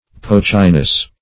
Poachiness \Poach"i*ness\